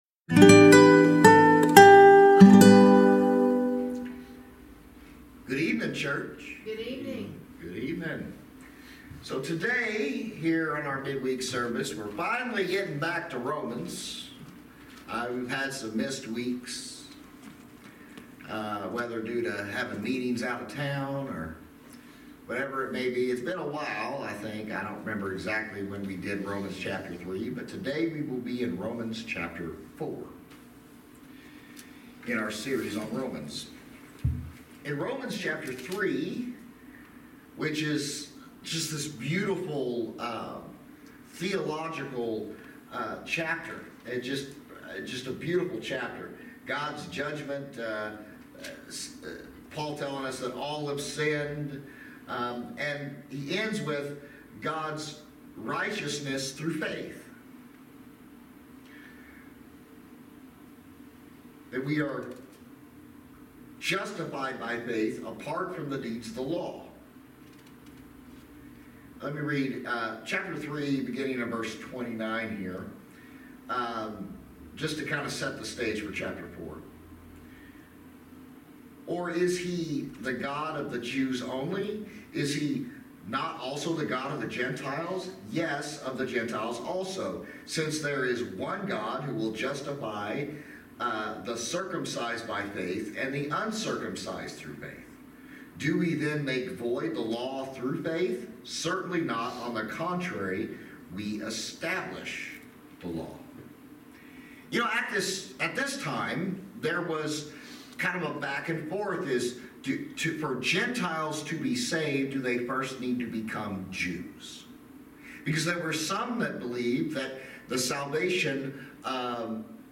Service Type: Thirsty Thursday Midweek Teaching